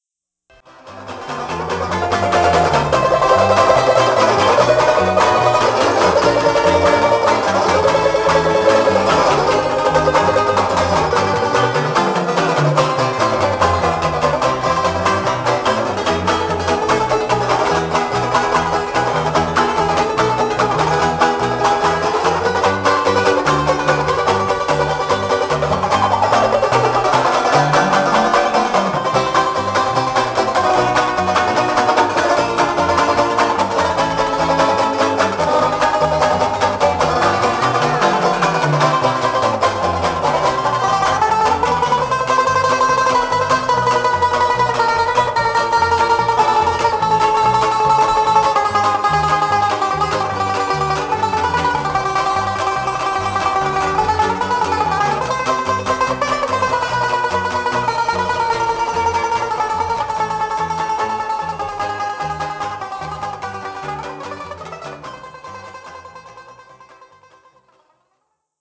8-beat intro.
This song is in 4/4 time.